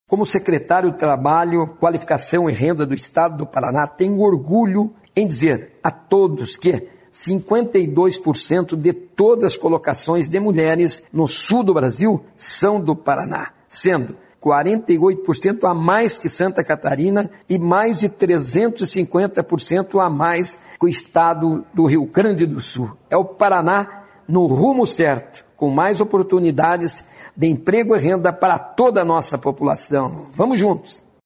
Sonora do secretário do Trabalho, Qualificação e Renda, Mauro Moraes, sobre os novos postos de trabalho ocupados por mulheres de janeiro a agosto de 2023 no Paraná